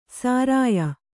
♪ sārāya